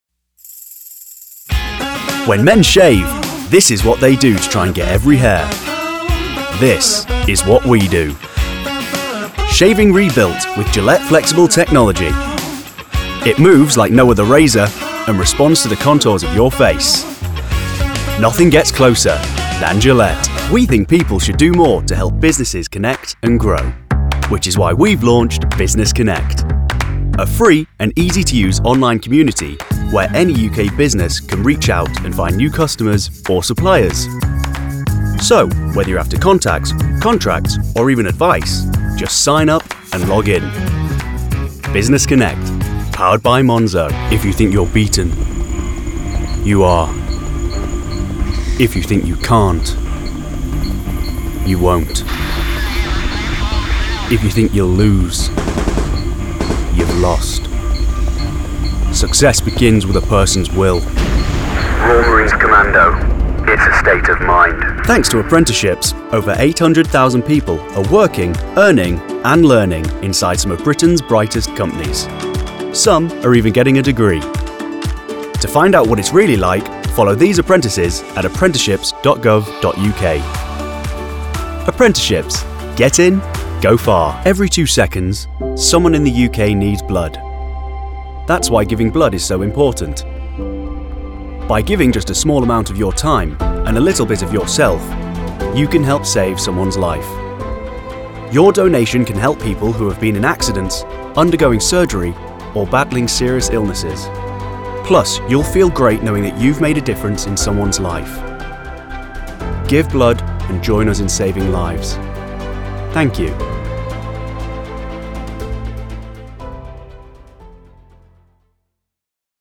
Voiceover Demos
Commercial Demo